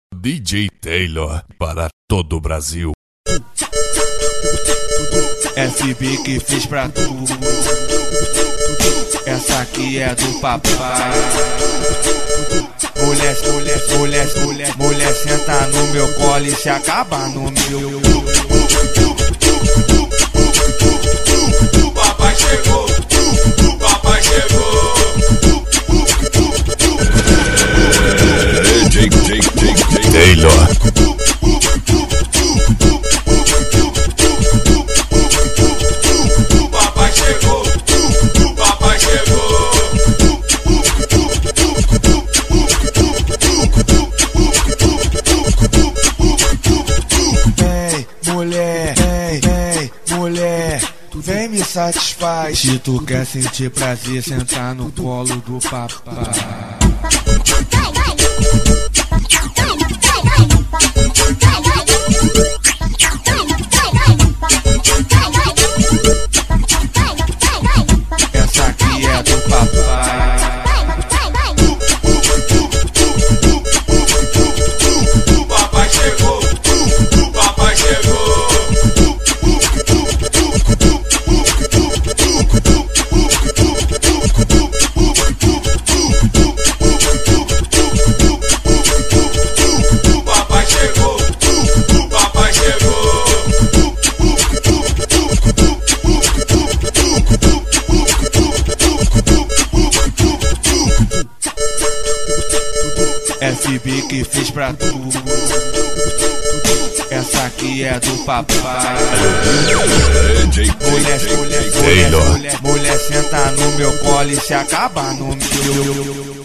remix.